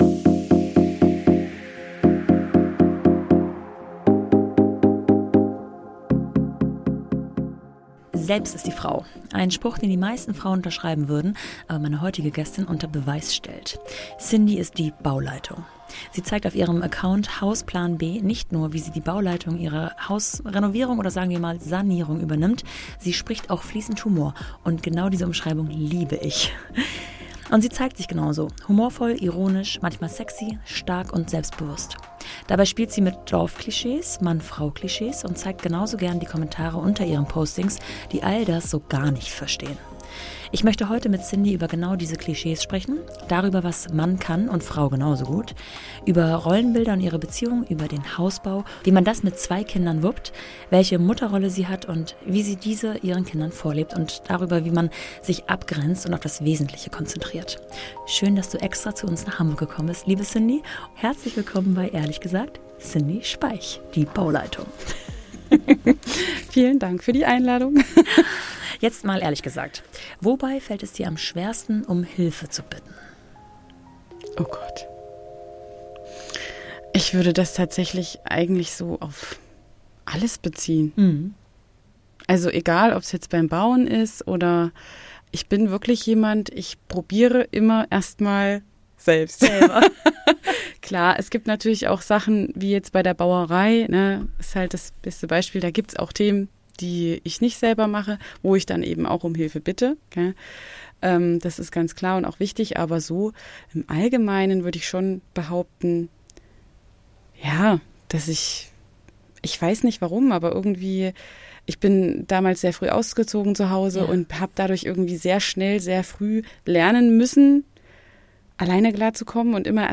In diesem Gespräch geht es um die Konzepte des „Großliebens“ und der Selbstbeziehung in der Erziehung.